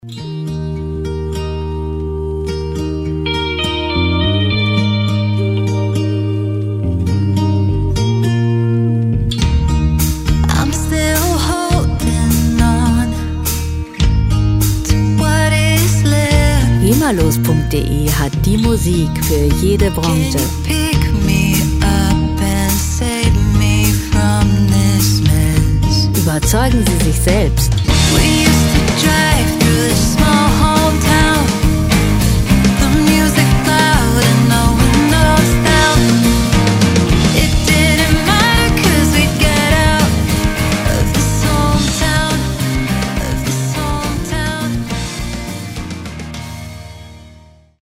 World Pop Music aus der Rubrik "Weltenbummler"
Musikstil: Indie Pop
Tempo: 84 bpm
Tonart: E-Dur
Charakter: intim, vertraut
Instrumentierung: E-Gitarre, E-Bass, Drums, Vocals